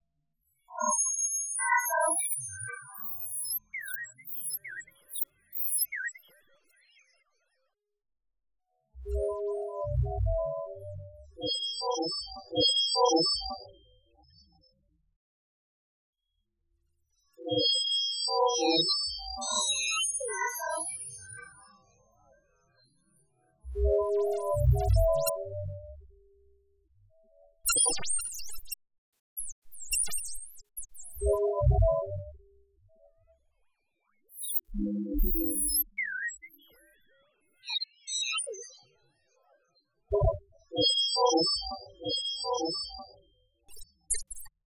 Interactive sound installation
spacey1.wav